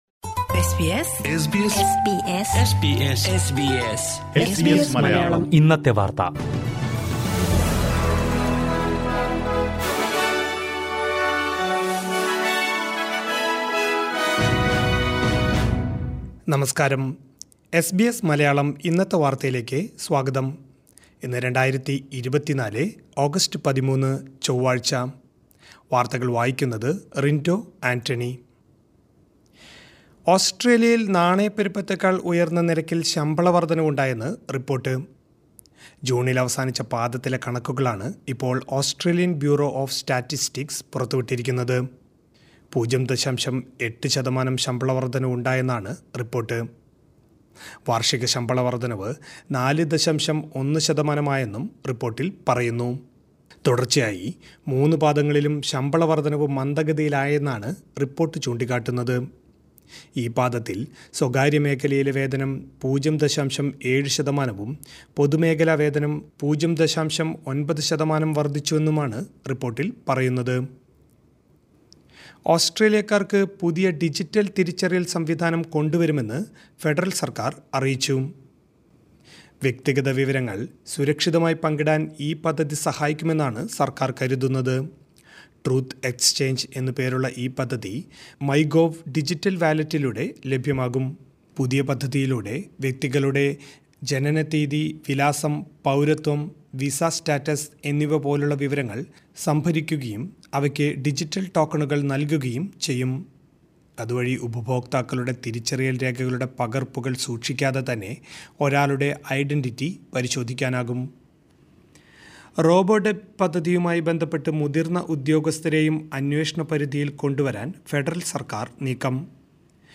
2024 ഓഗസ്റ്റ് 13ലെ ഓസ്‌ട്രേലിയയിലെ ഏറ്റവും പ്രധാന വാര്‍ത്തകള്‍ കേള്‍ക്കാം...